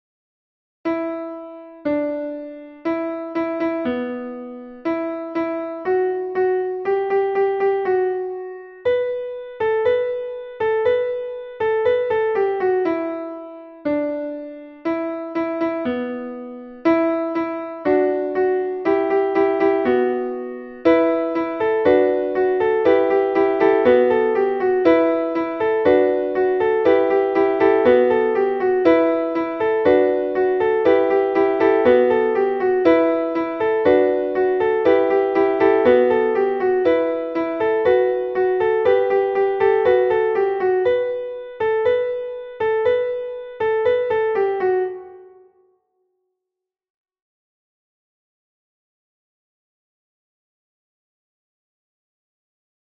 *Sung as a Round